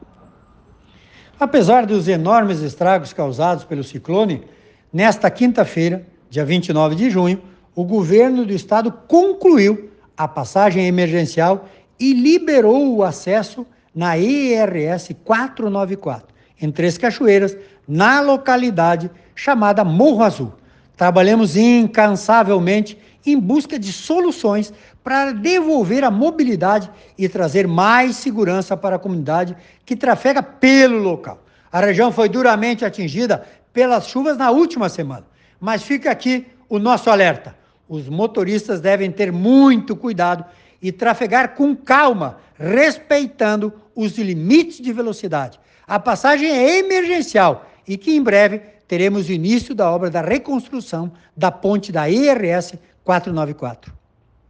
Juvir Costella fala sobre conclusão de desvio na ERS-494